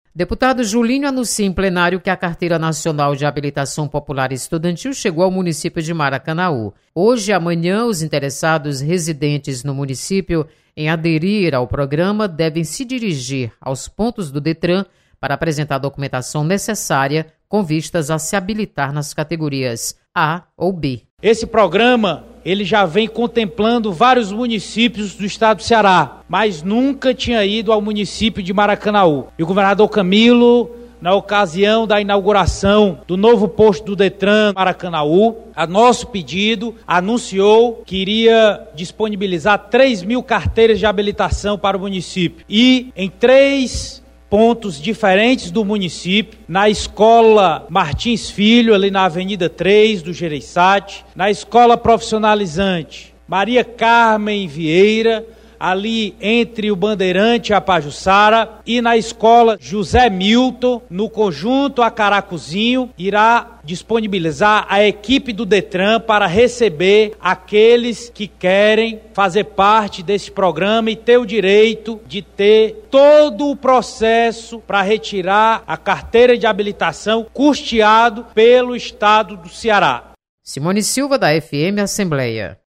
Deputado Julinho relata chegada de programa que permite gratuidade para estudantes conseguirem CNH. Repórter